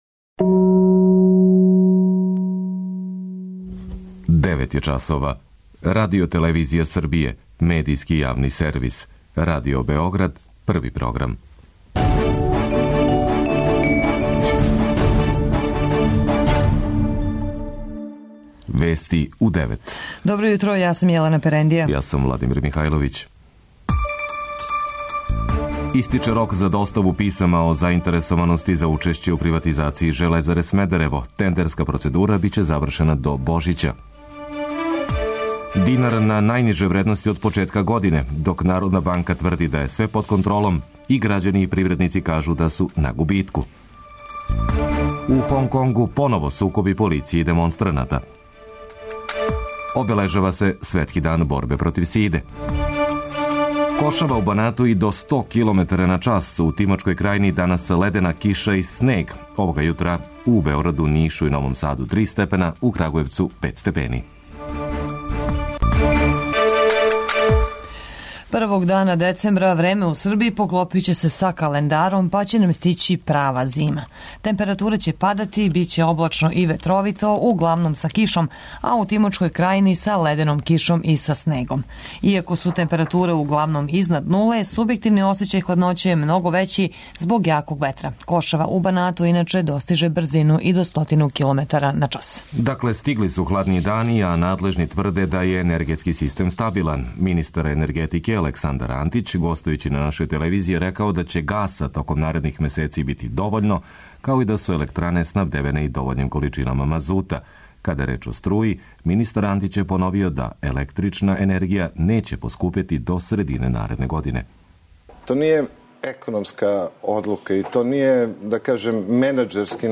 преузми : 10.45 MB Вести у 9 Autor: разни аутори Преглед најважнијиx информација из земље из света.